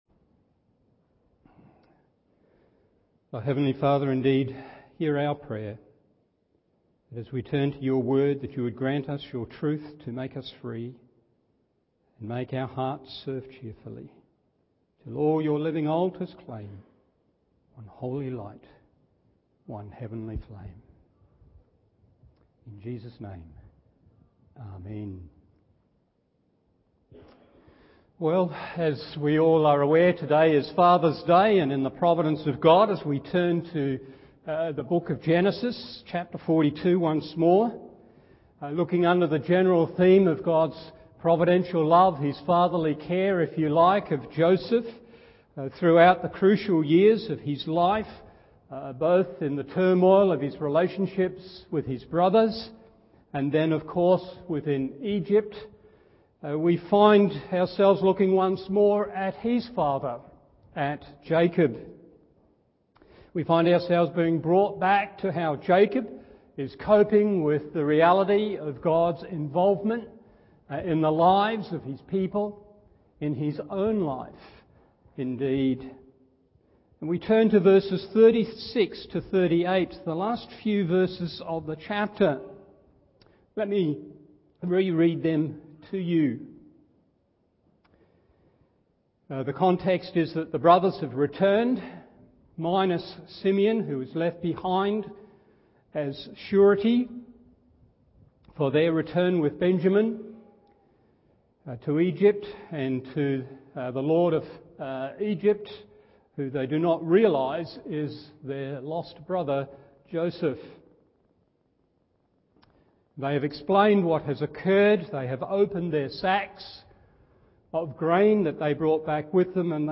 Morning Service Genesis 42:36-38 1.